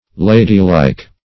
Ladylike \La"dy*like`\, a.